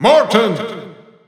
Announcer pronouncing Morton in German.
Morton_German_Announcer_SSBU.wav